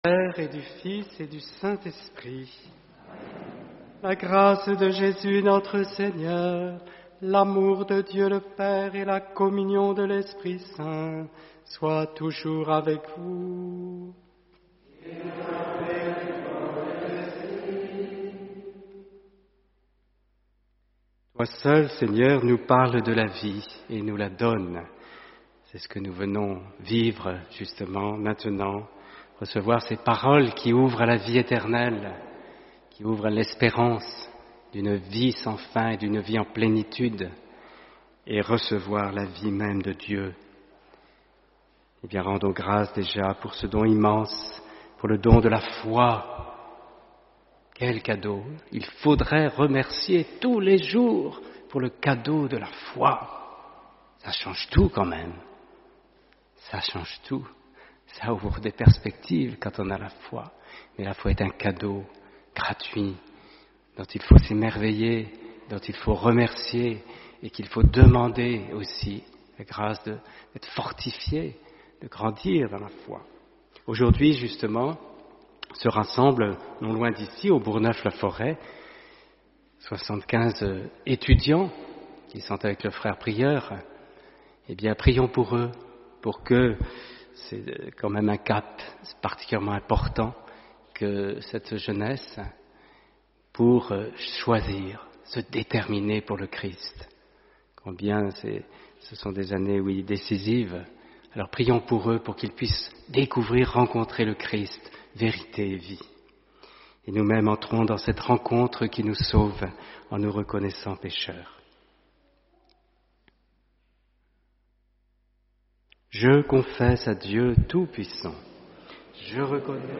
Homélie : Fidélité.